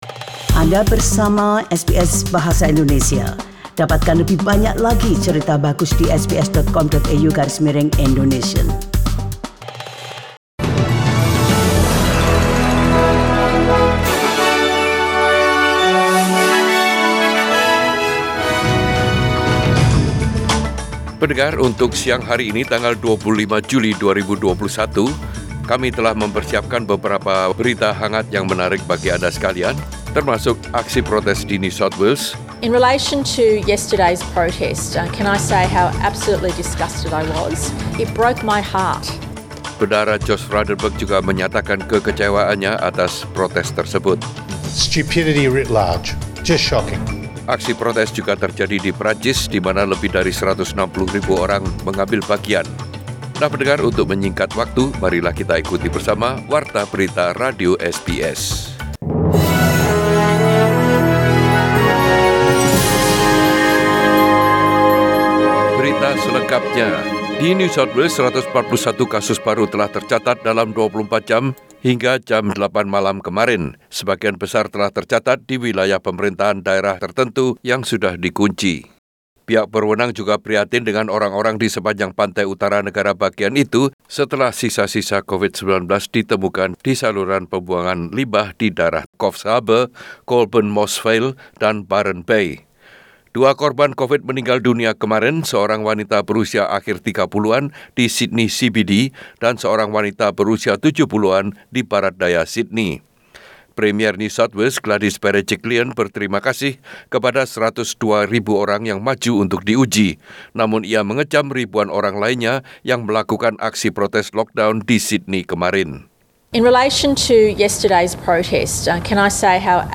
Warta Berita Radio SBS dalam Bahasa Indonesia Source: SBS